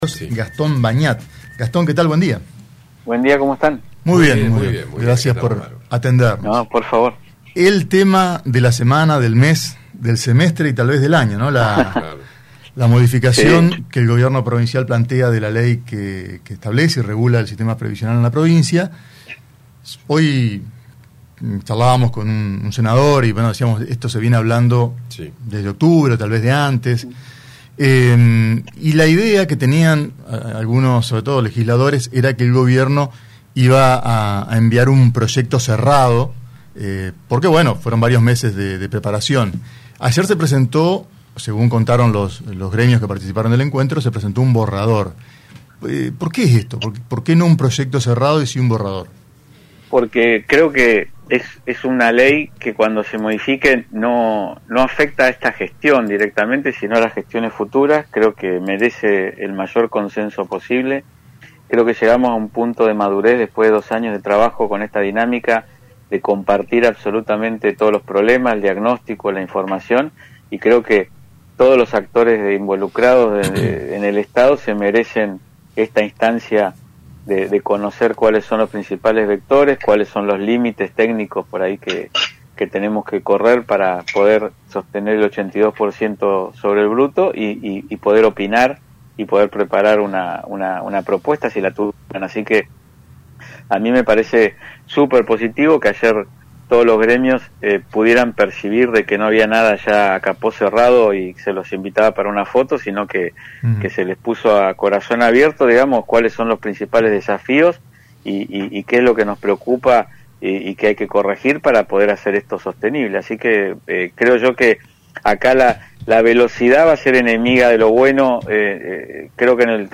entrevista
por Radio Costa Paraná (88.1)